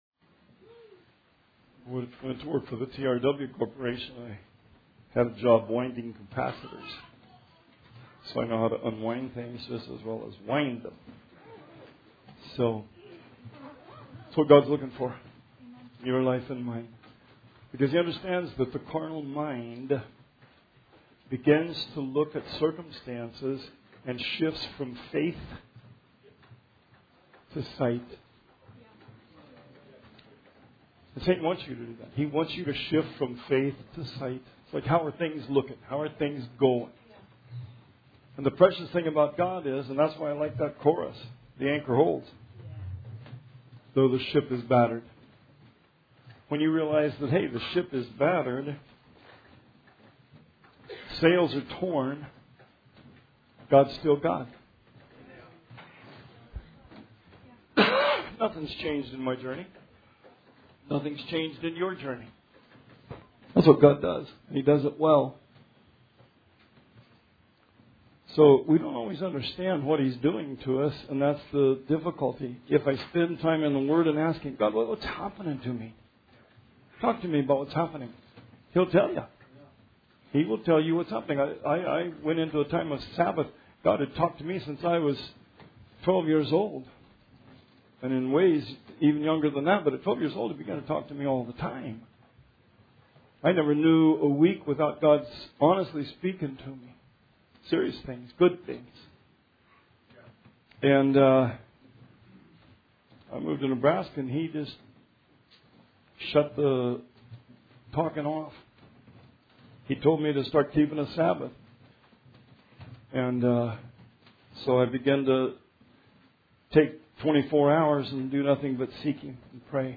Sermon 10/6/19 – RR Archives